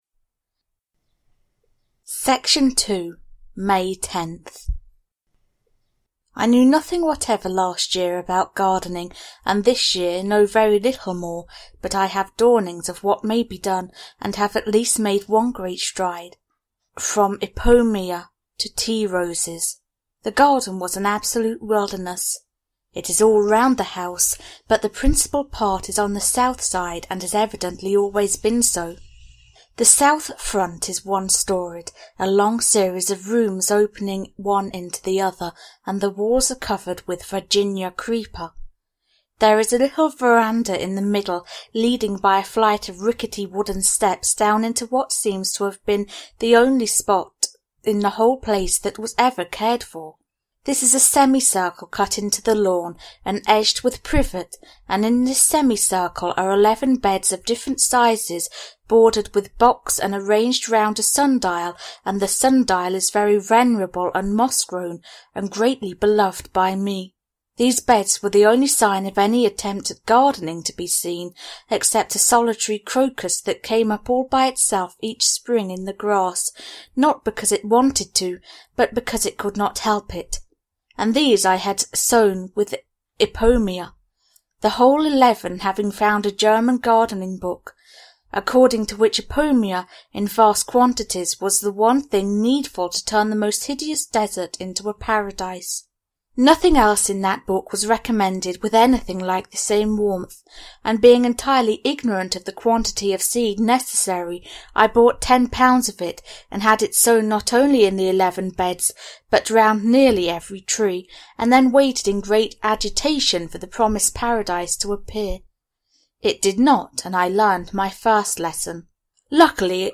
Elizabeth and her German Garden – Ljudbok – Laddas ner